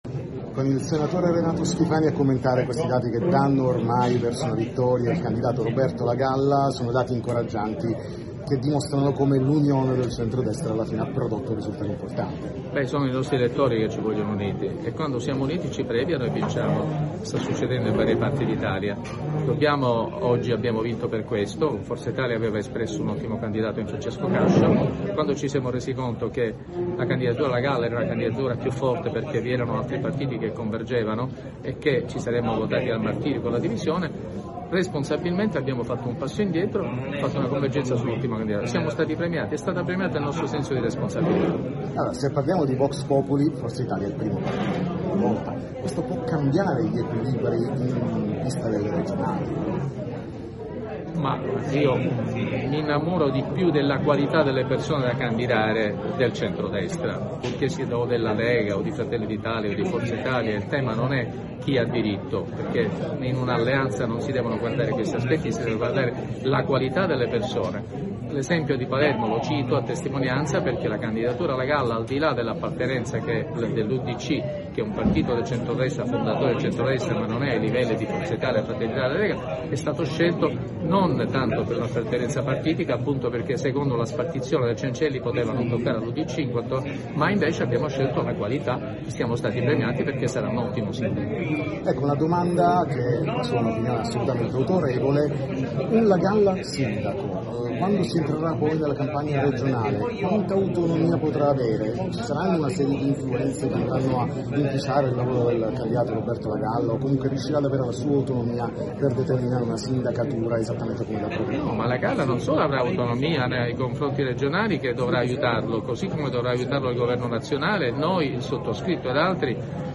TM Intervista Schifani